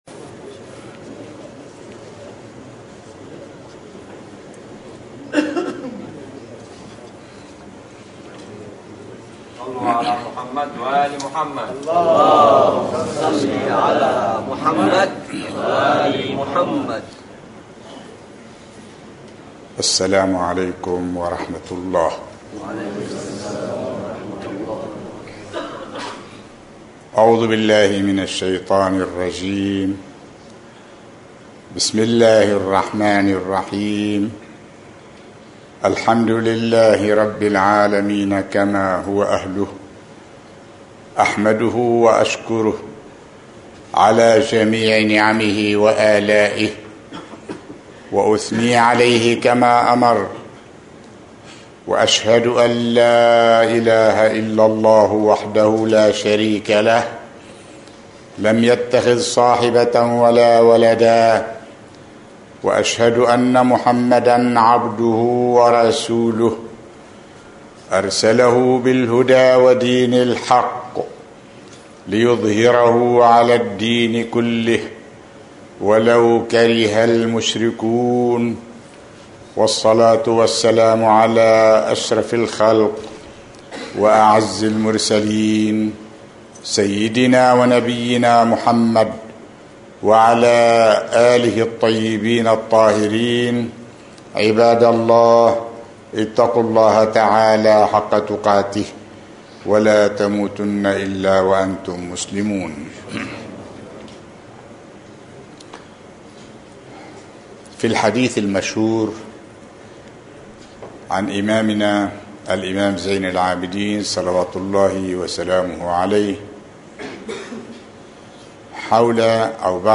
خطبة الجمعة
في مسجد المؤسسة الإسلامية الاجتماعية في دكار